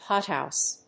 Pot+House.mp3